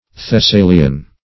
Search Result for " thessalian" : Wordnet 3.0 NOUN (1) 1. a native or inhabitant of Thessaly ; The Collaborative International Dictionary of English v.0.48: Thessalian \Thes*sa"li*an\, a. [Cf. L. Thessalius.] Of or pertaining to Thessaly in Greece.